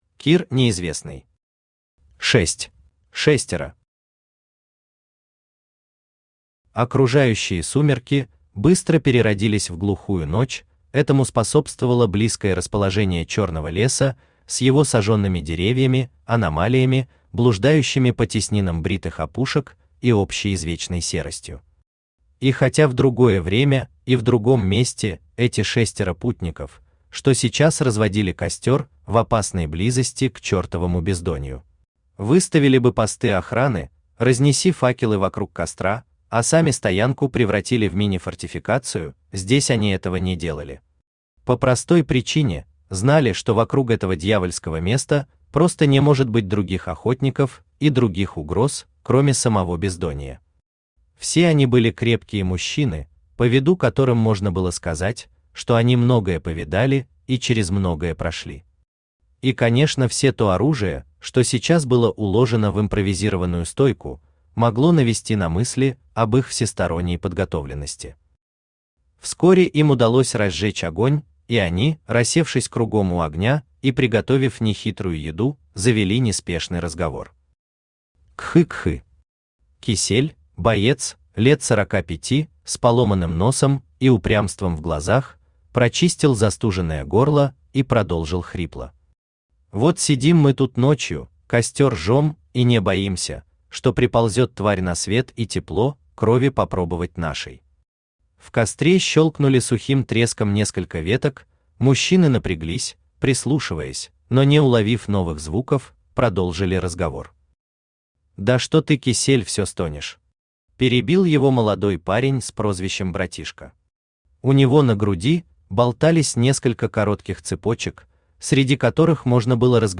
Aудиокнига Шесть Автор Кир Николаевич Неизвестный Читает аудиокнигу Авточтец ЛитРес.